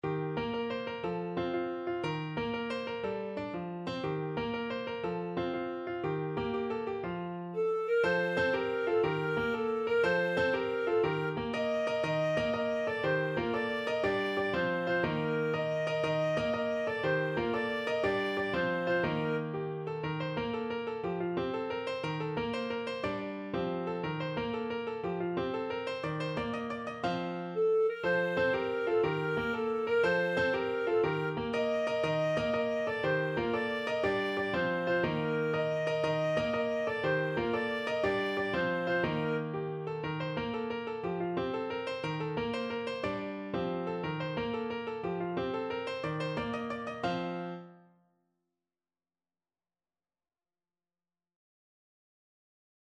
6/8 (View more 6/8 Music)
With energy .=c.120
Clarinet  (View more Easy Clarinet Music)
Classical (View more Classical Clarinet Music)